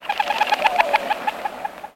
Dove Coos With Wings